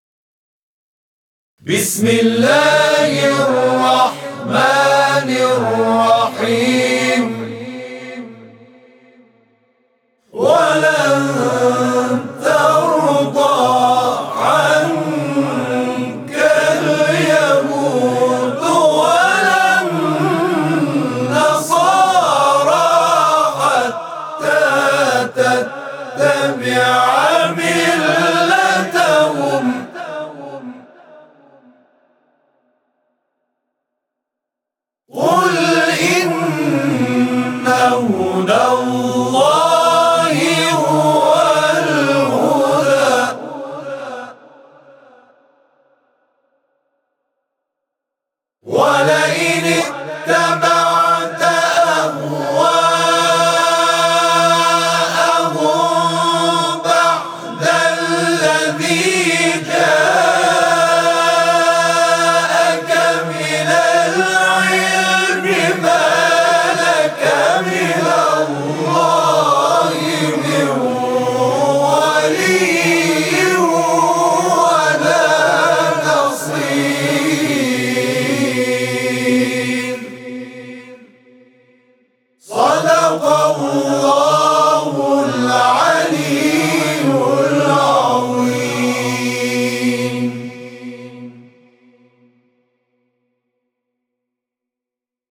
همخوانی آیات 120 و 216 سوره «بقره» را از سوی گروه همخوانی و تواشیح «محمد رسول‌الله(ص)» در راستای نهضت ملی «زندگی با آیه‌ها» می‌شنوید.
صوت همخوانی آیه 120 سوره مبارکه «بقره»/ روز اول رمضان